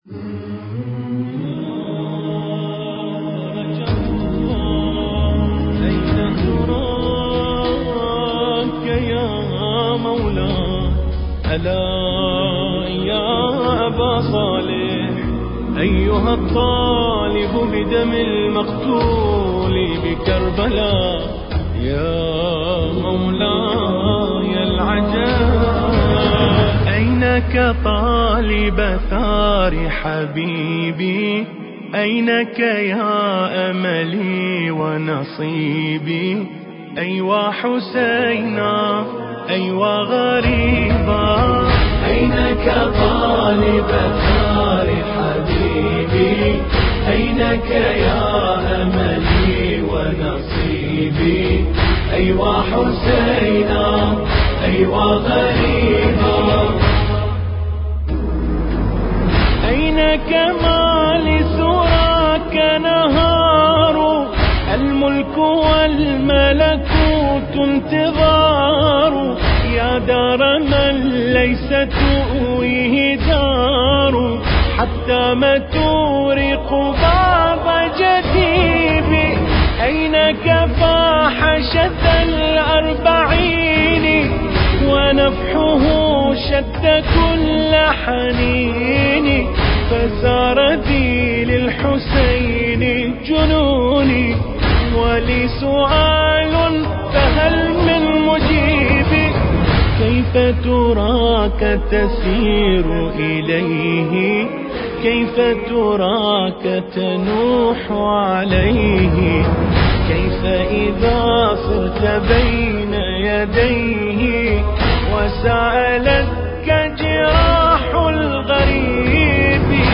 المراثي
القارئ : حسين الأكرف